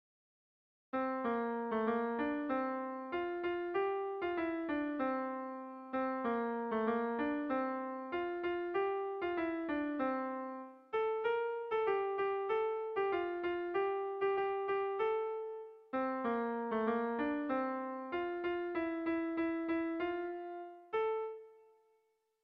Gabonetakoa
Zortziko txikia (hg) / Lau puntuko txikia (ip)
AABA2